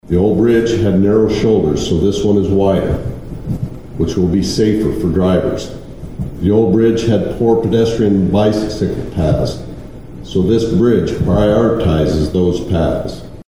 During the ribbon cutting, South Dakota Governor Larry Rhoden shared some history about the structures.